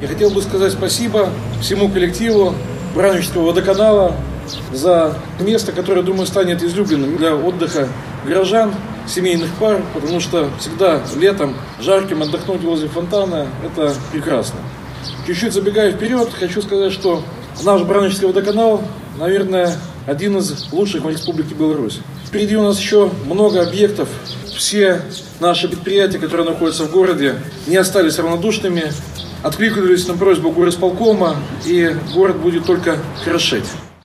На церемонии открытия присутствовал мэр города Михаил Баценко. Отрадно, что празднование «Барановичской весны» началось с очередного объекта благоустройства – запуска фонтана, — отметил Михаил Баценко.